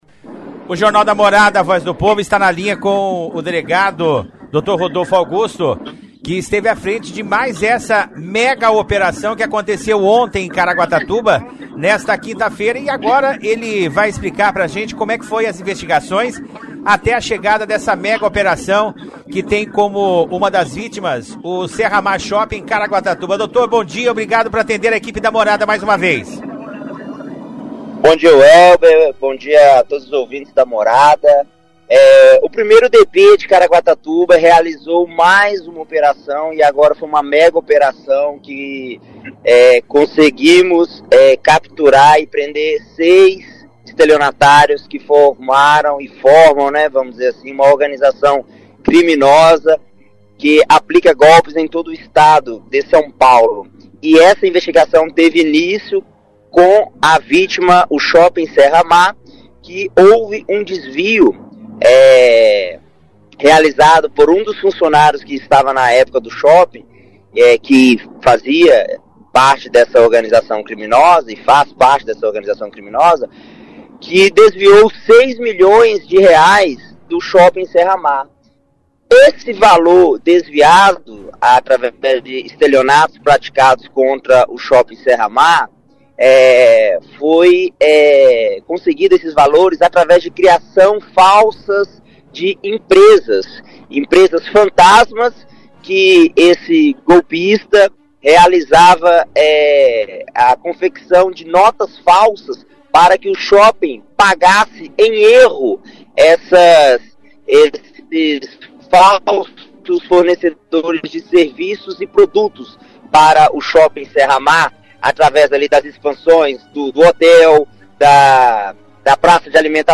entrevista
entrevista.mp3